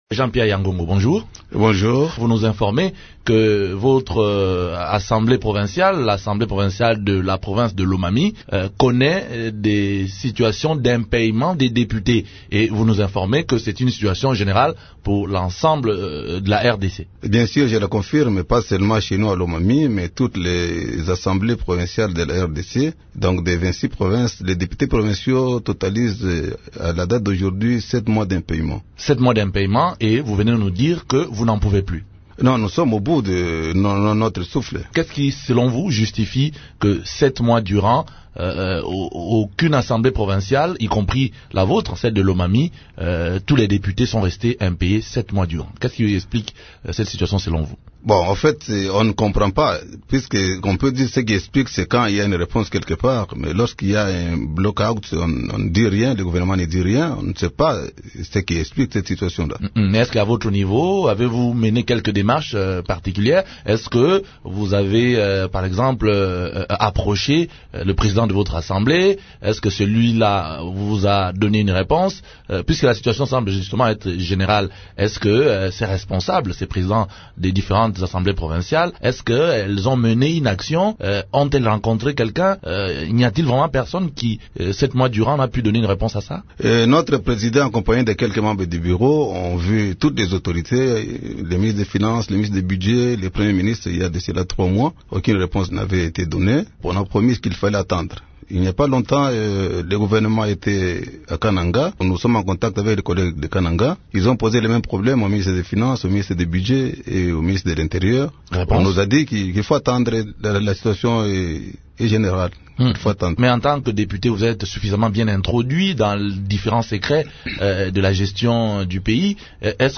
Pour en parler, Radio Okapi reçoit jeudi 14 septembre le député provincial de la Lomami, Jean-Pierre Yangongo.